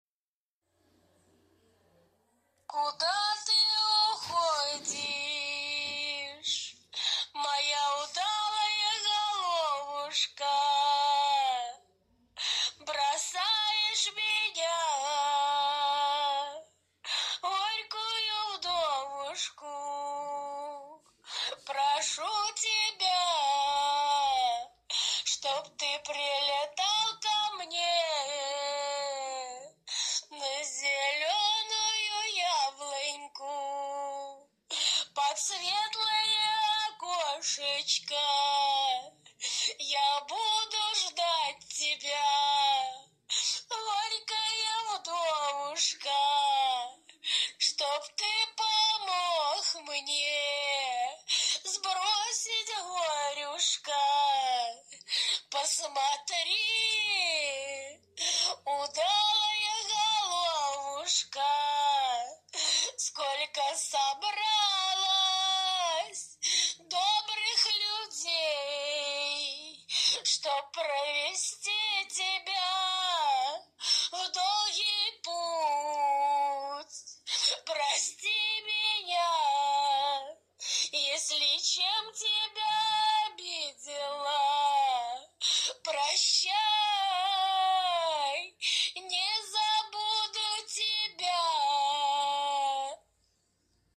Плач вдовы